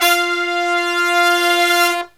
LONG HIT06-L.wav